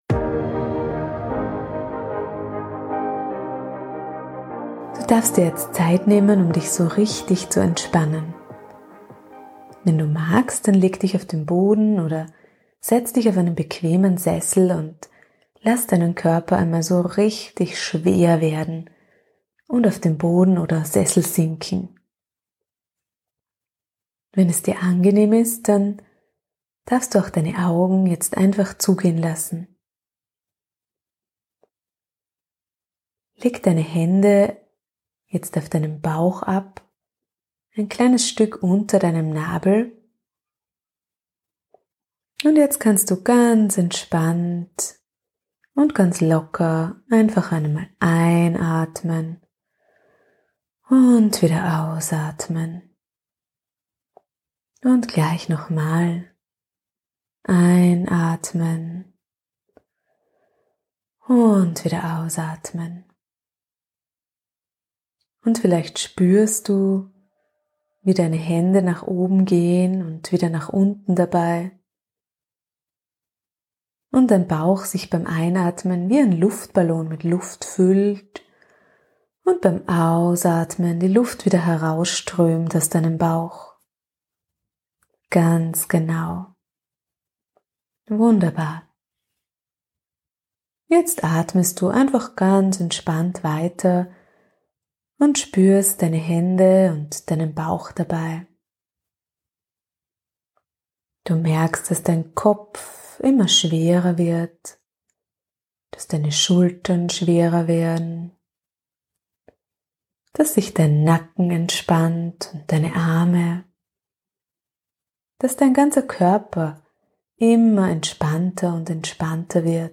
#78 Phantasiereise für Kinder: "Dein innerer Kraftort"